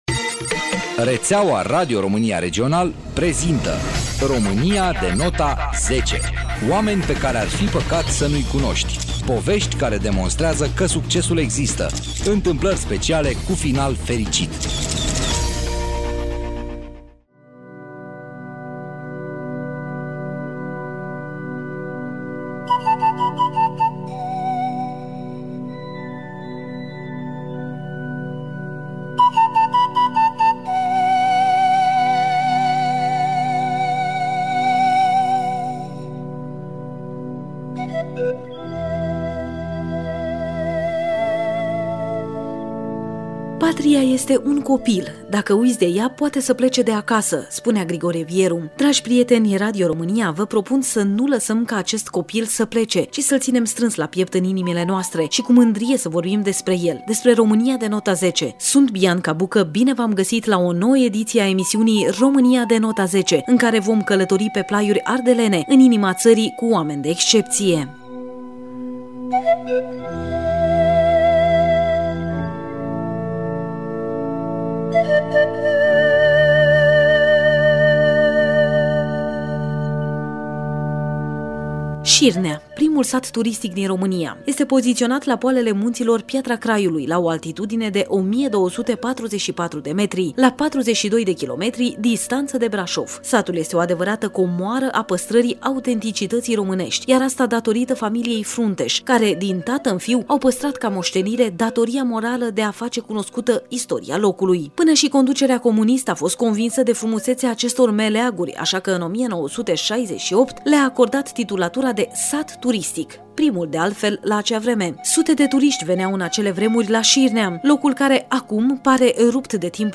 Echipa Radio România Brașov FM a în poposit în inima țării pentru a se întâlni cu oameni de excepție în Șirnea – primul sat turistic din România.